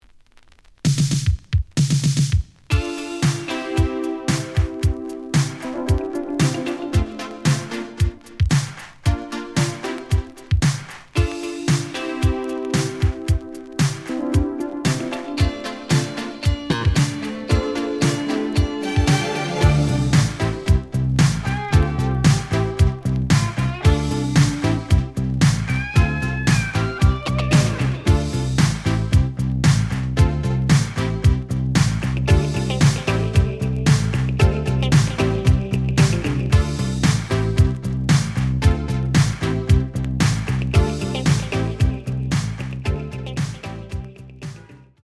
(Instrumental)
The audio sample is recorded from the actual item.
●Genre: Disco